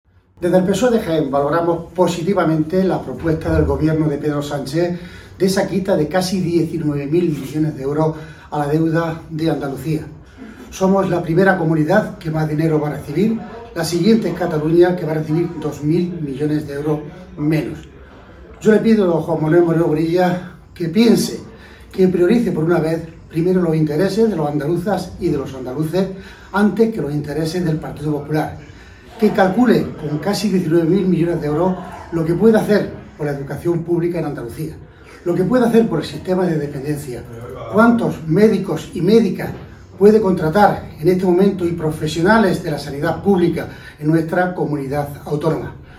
Cortes de sonido z Francisco Reyes